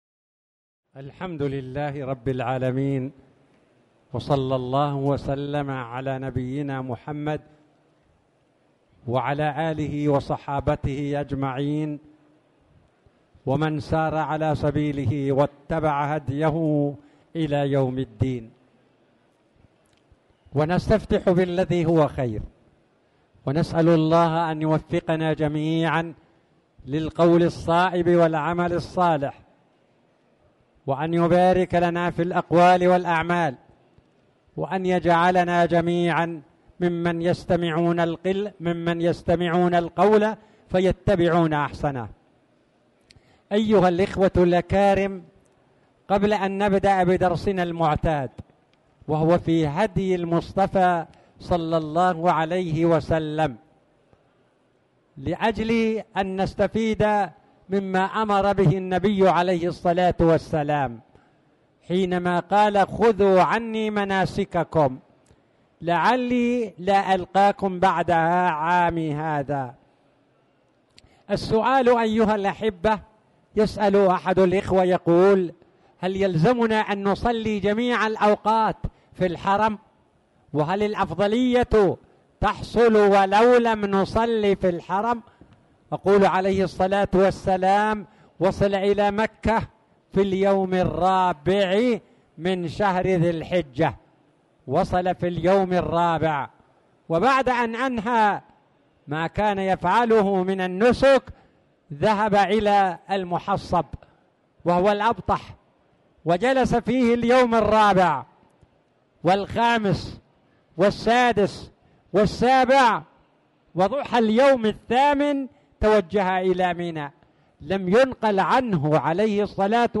تاريخ النشر ١٧ صفر ١٤٣٨ هـ المكان: المسجد الحرام الشيخ